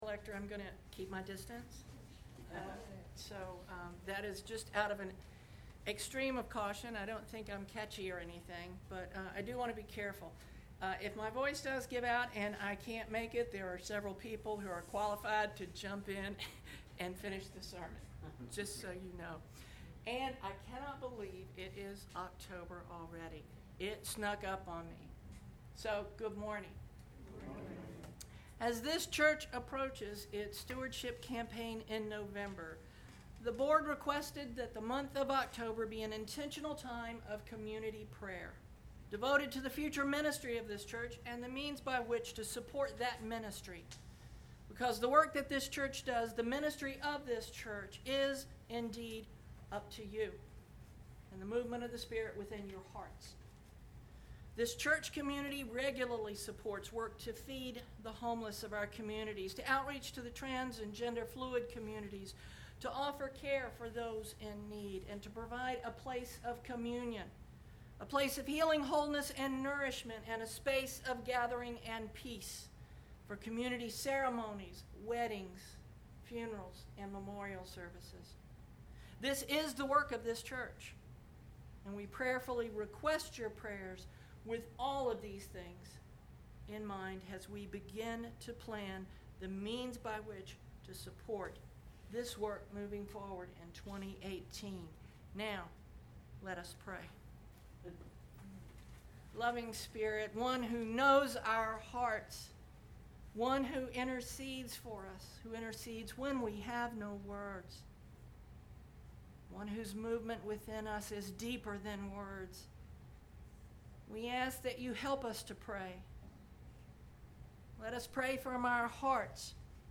10/01 Sermon Posted